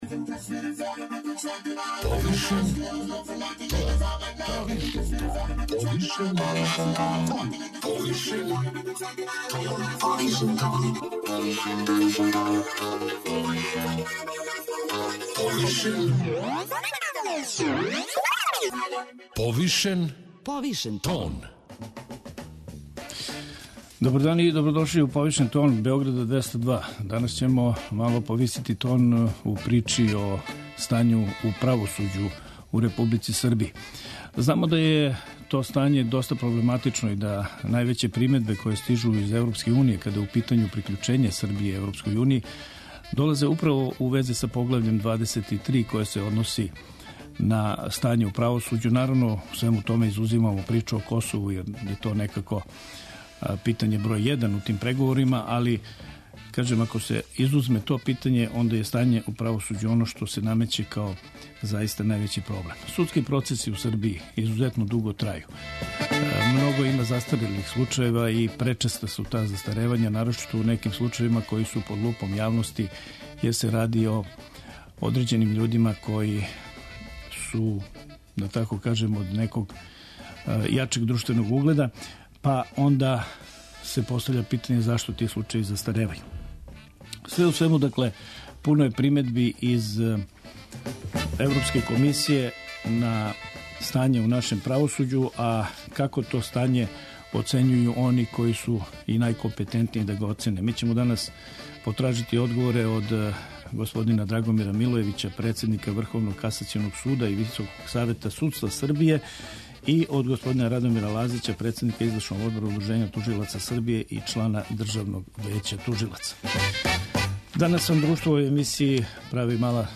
Зашто каснимо са тим променама, шта би све требало мењати и колико је објективних фактора који утичу на опште стање у правосуђу питања су на које одговоре тражимо од председника Врховног касационог суда и Врховног савета судства Драгомира Милојевића , и члана Државног већа тужилаца и председника УО Удружења тужилаца Србије Радомира Лазића .